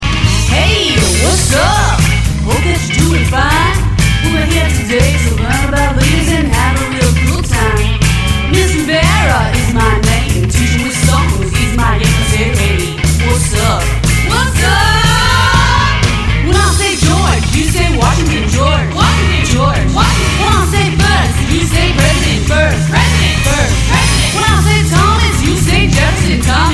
Vocal Track
Instrumental Version